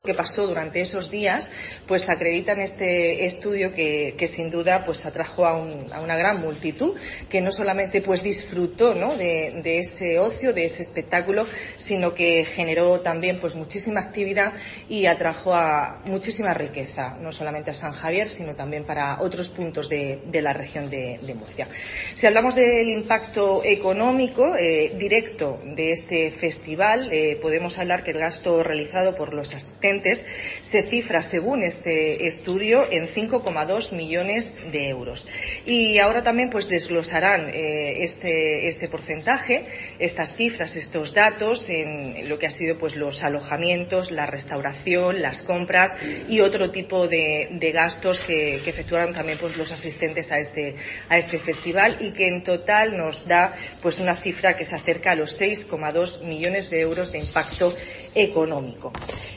Carmen María Conesa, consejera de Turismo, Cultura, Juventud y Deportes